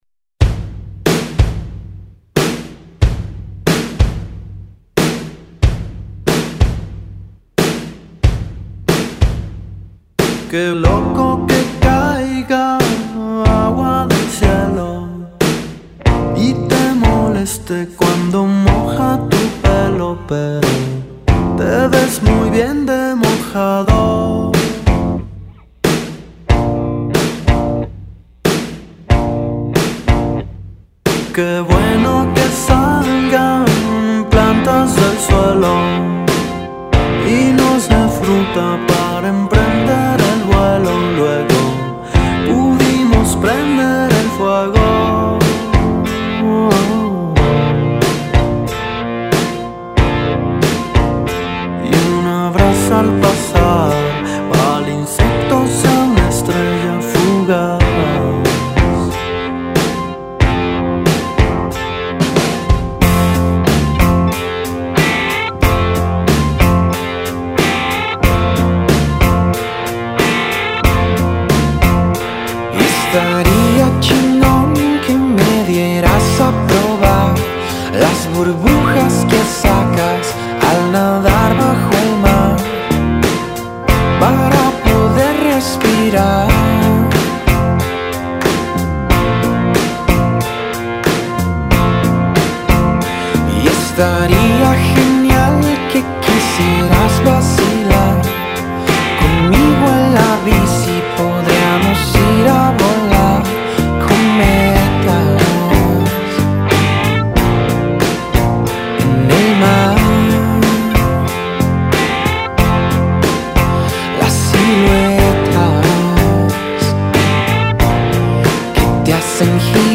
Me pareció un estilo muy agradable.